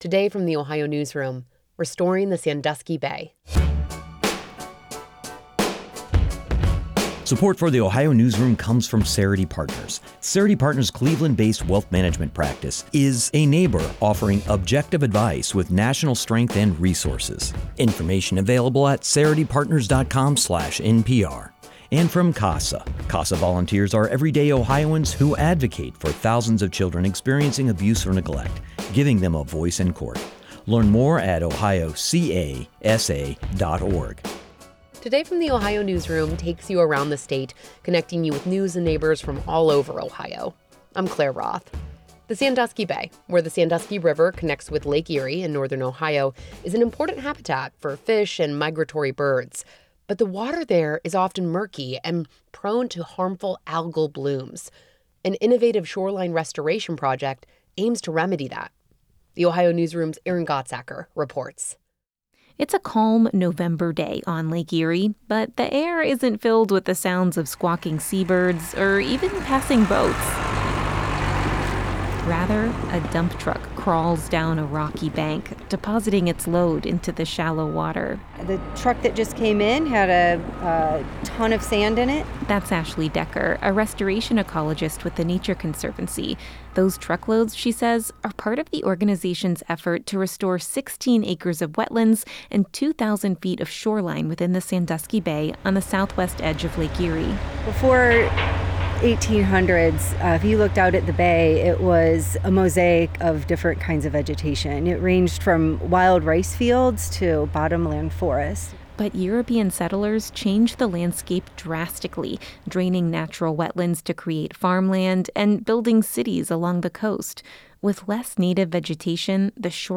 On a calm November day on Lake Erie, the air wasn't filled with the sounds of squawking seabirds or even passing boats.
Rather, a dump truck rumbled down a rocky bank, depositing its load into the shallow water.